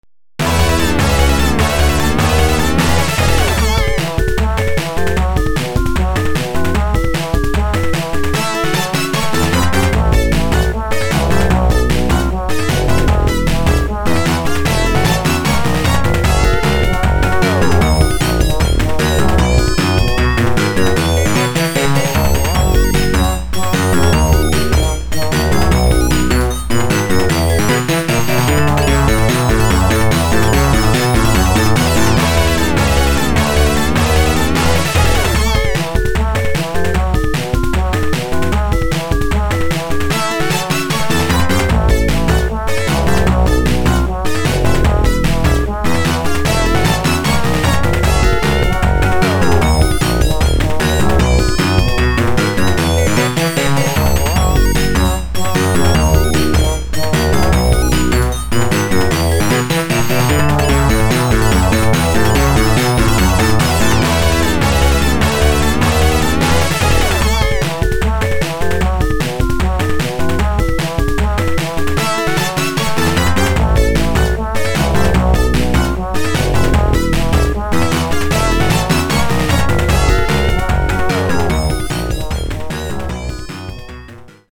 DL - Jackpot Sound:
funhouse_jackpot.mp3